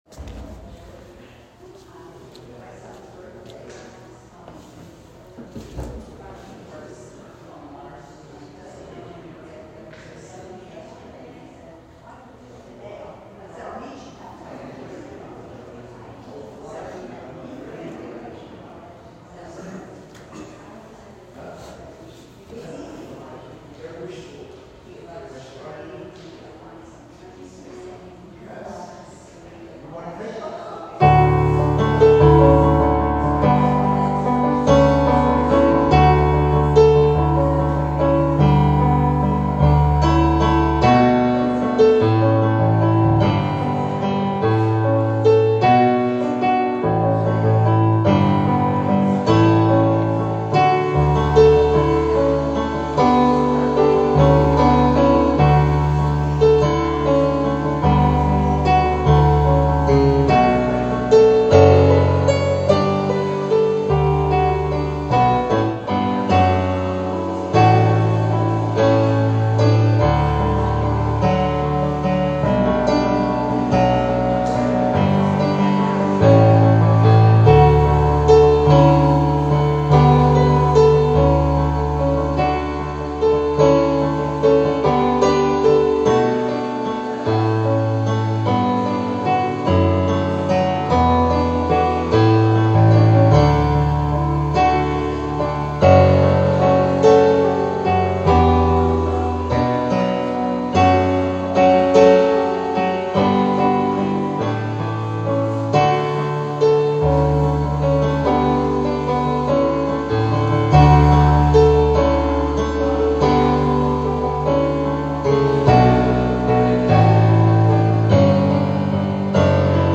Taizé Prayer Service